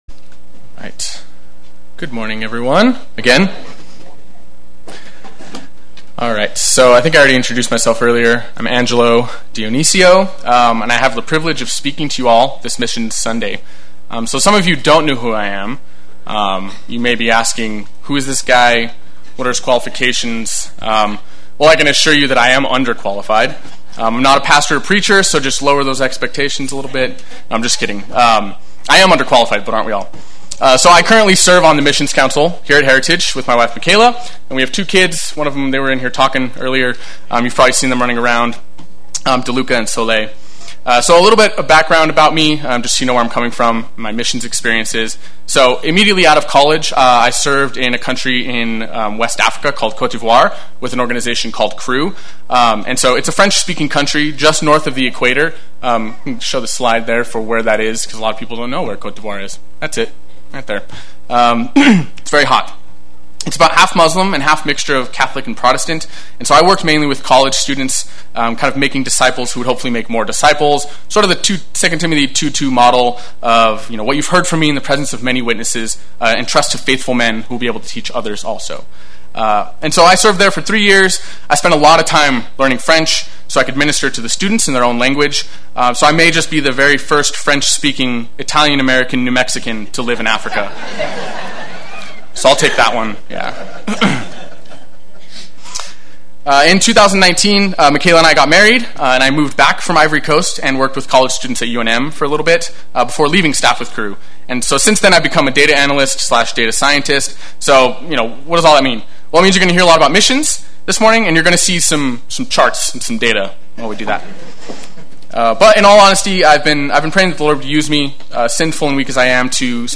Play Sermon Get HCF Teaching Automatically.
Missions Sunday Worship